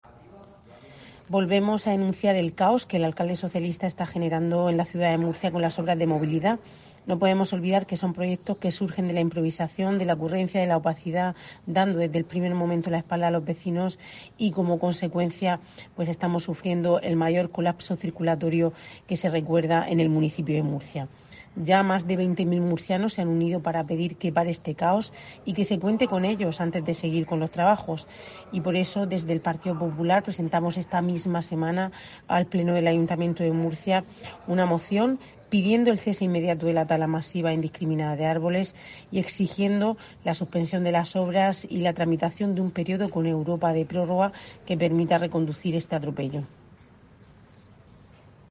Rebeca Pérez, portavoz del PP en el Ayuntamiento de Murcia